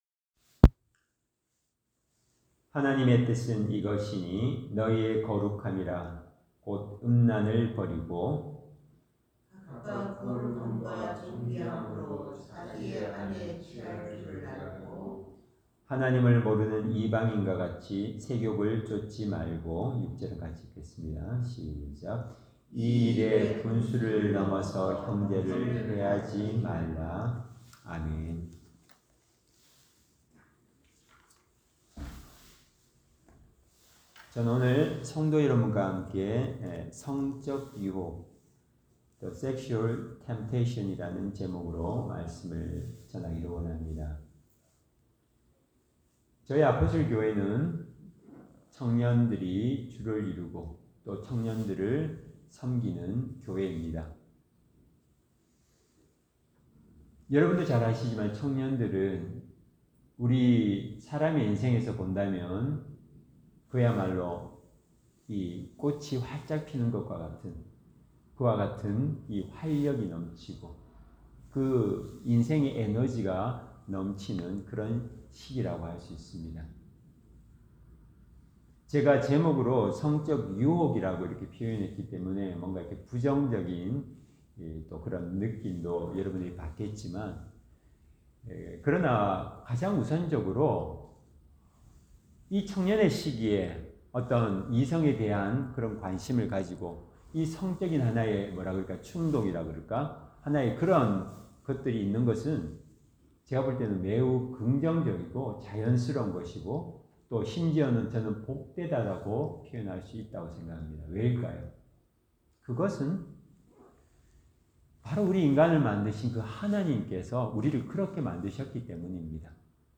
Service Type: 주일 예배